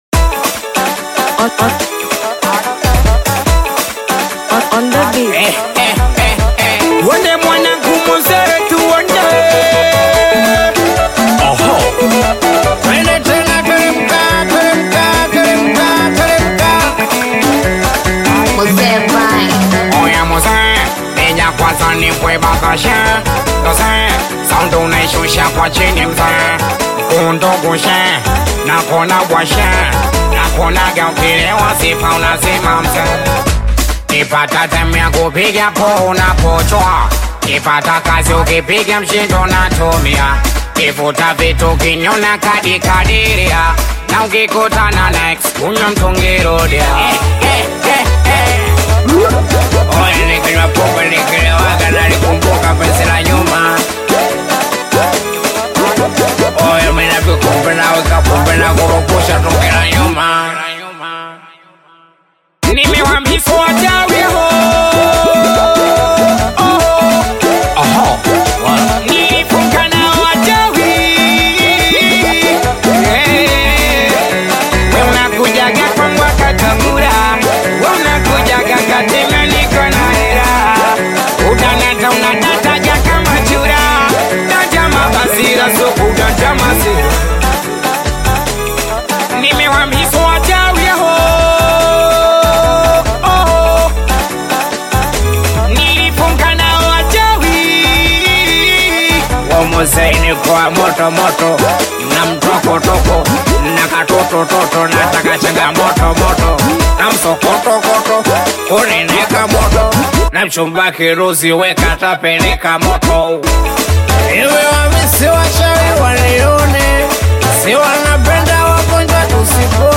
Singeli You may also like